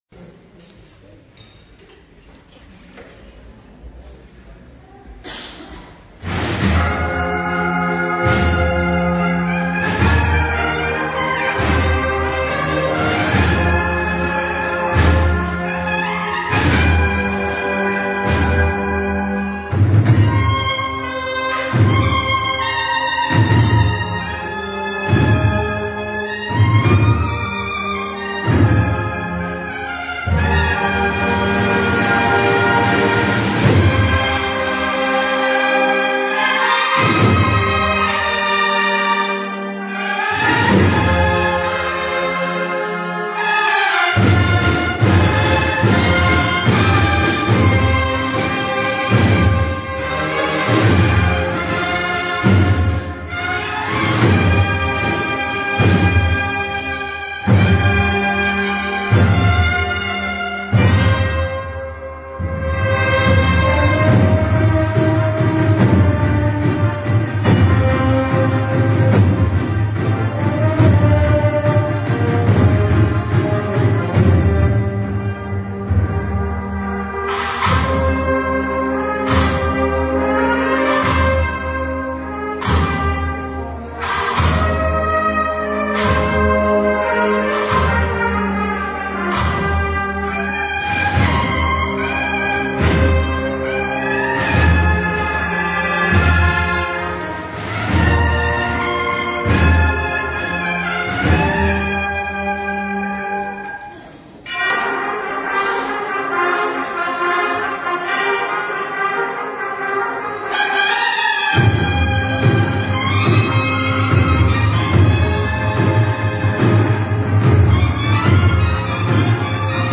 Marchas dedicadas al Santísimo Cristo de la Redención